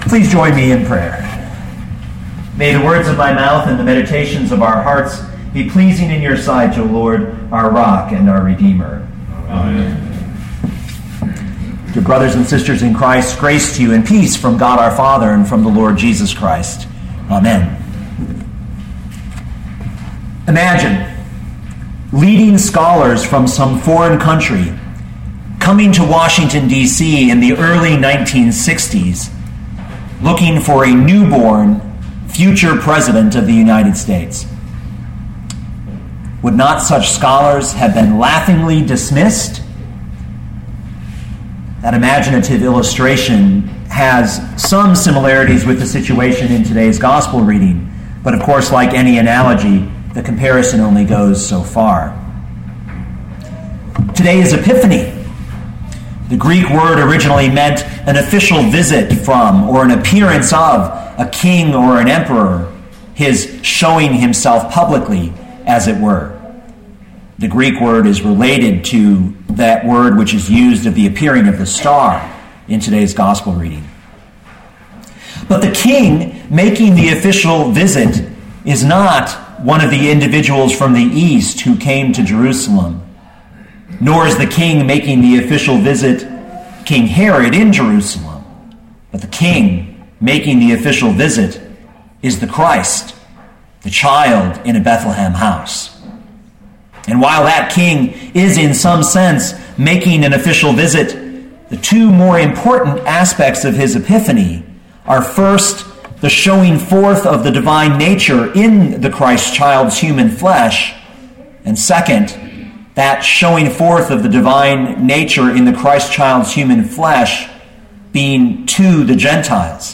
2013 Matthew 2:1-12 Listen to the sermon with the player below, or, download the audio.